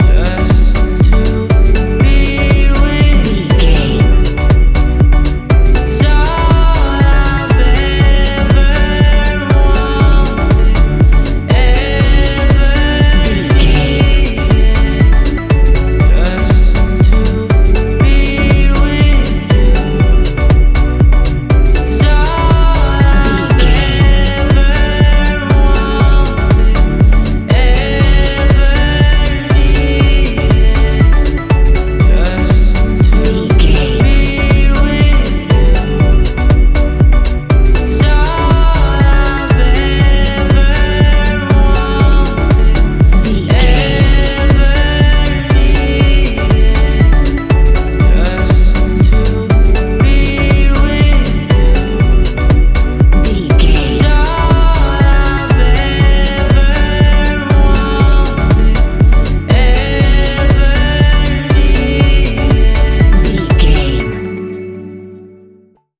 Ionian/Major
E♭
house
electro dance
synths
trance